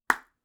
• pop sound 2.wav
Recorded with a Steinberg Sterling Audio ST66 Tube, in a small apartment studio. Popping a small chocolate egg cap.